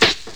SNARE 30.wav